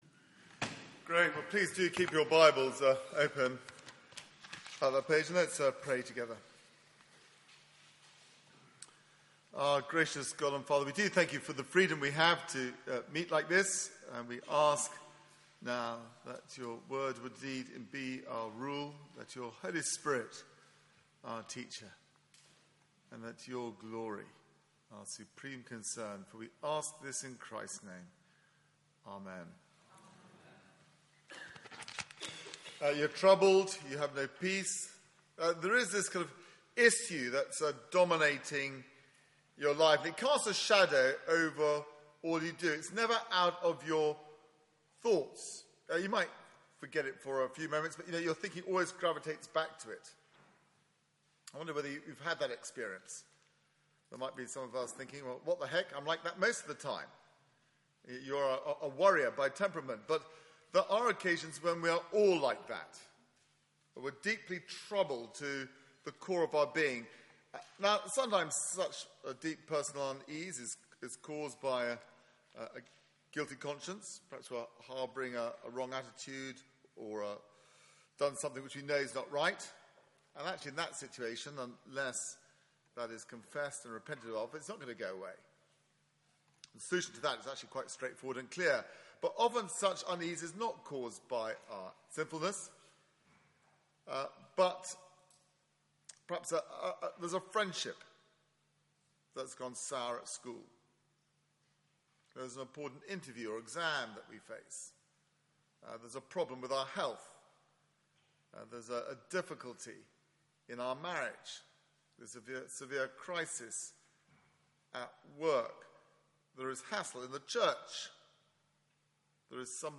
Media for 9:15am Service on Sun 29th May 2016
Passage: John 13:36-14:14 Series: The gathering storm Theme: Apprehensive but assured Sermon